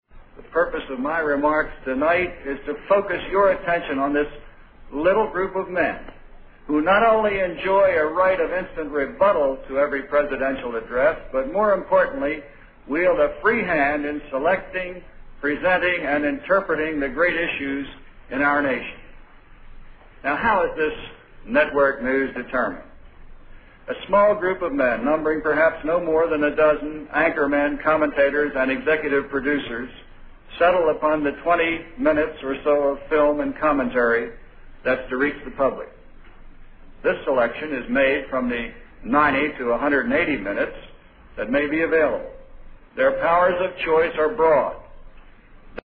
经典名人英语演讲(中英对照):Television News Coverage 5